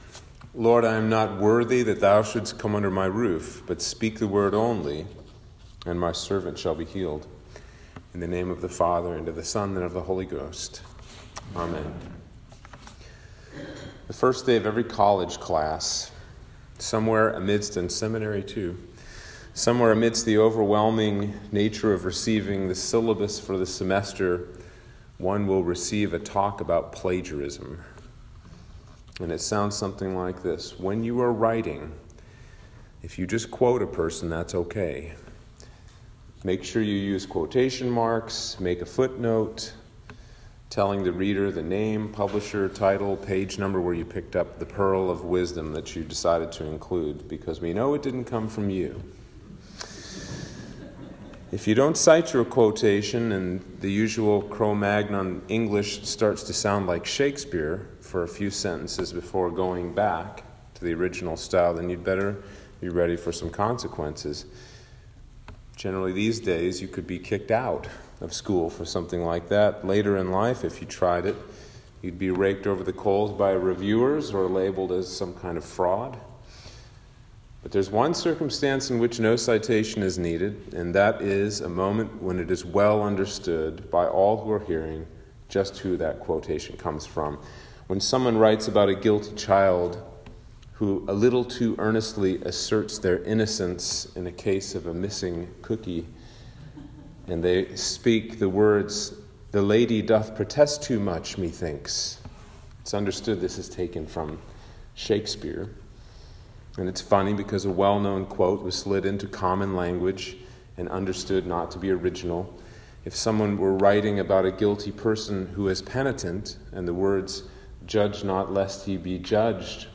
Sermon for Epiphany 4 - 2022